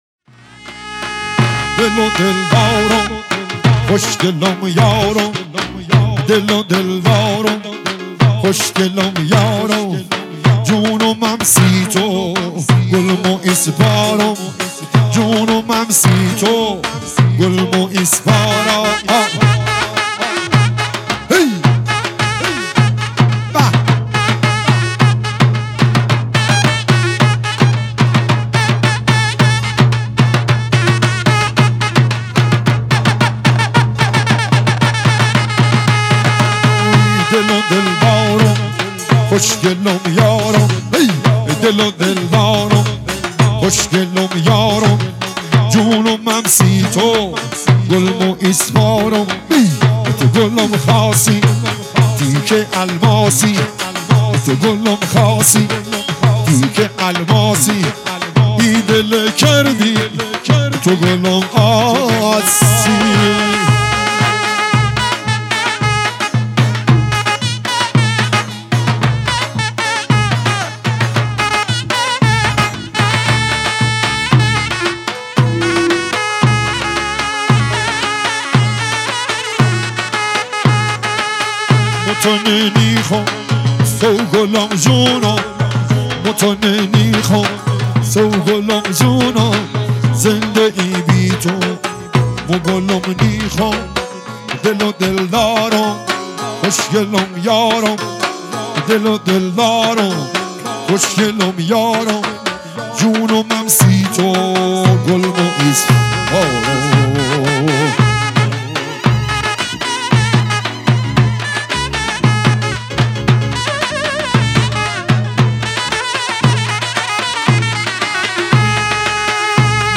محلی لری عروسی